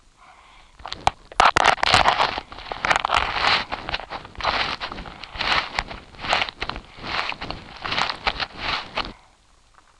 chewing.wav